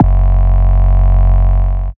Index of /Antidote Advent/Drums - 808 Kicks
808 Kicks 10 F#.wav